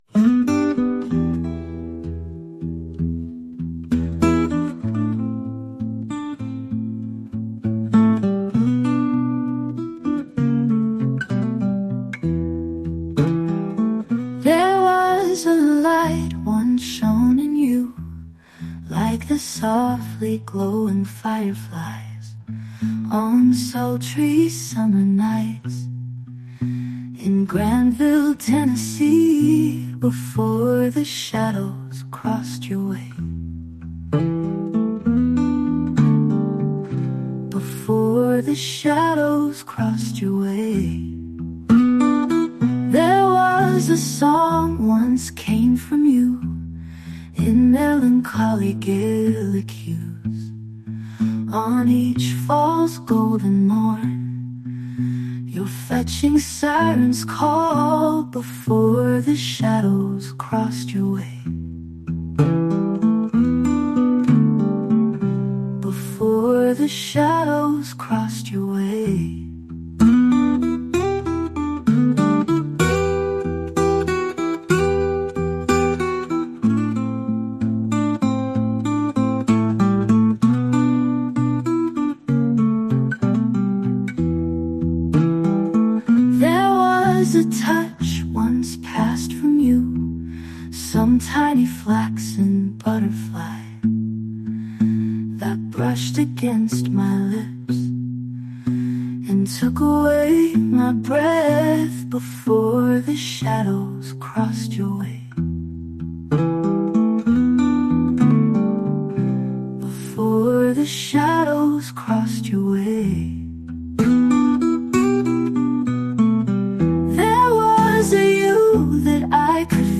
Melancholy write set to great music! I enjoy the vocalist as well.